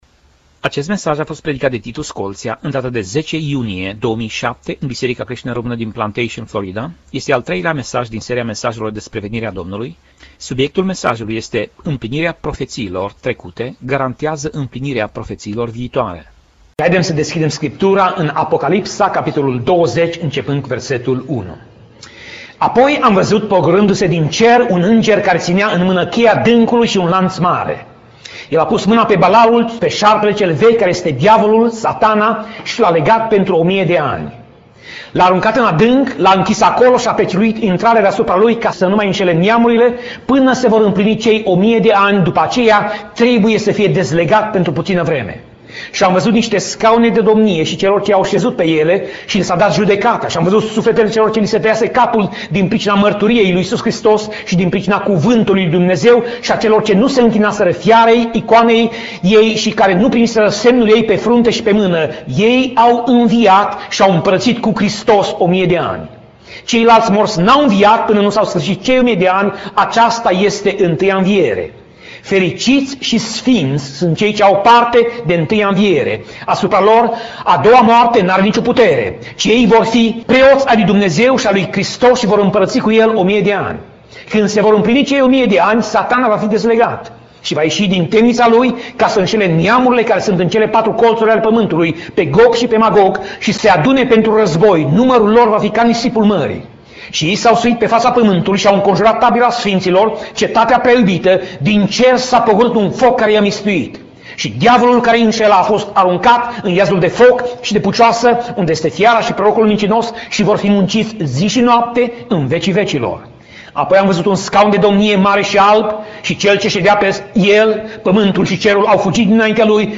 Pasaj Biblie: Apocalipsa 20:1 - Apocalipsa 20:15 Tip Mesaj: Predica